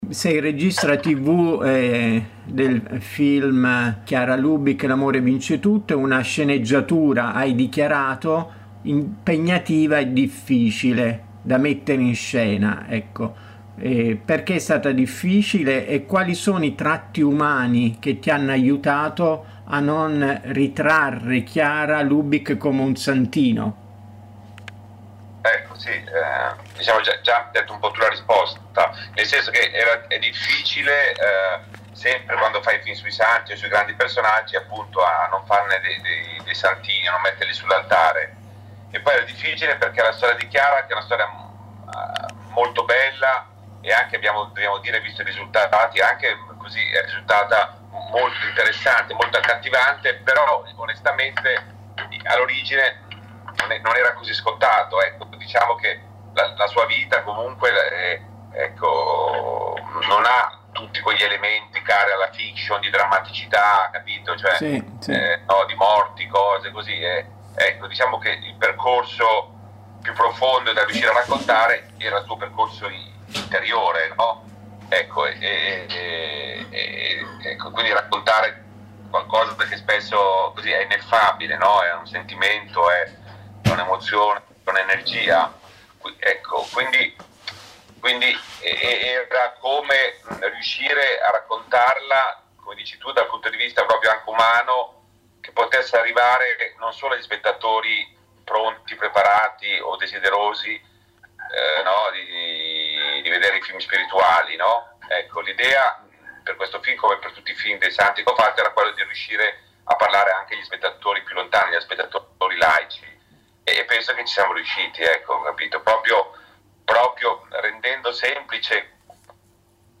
Cultura > Audioletture
In questa intervista il cineasta spiega cosa rappresenta per lui e nel mondo di oggi la figura di Chiara e che immagine ha voluto darne nel film.